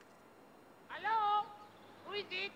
Play, download and share French Hello original sound button!!!!
french-hello.mp3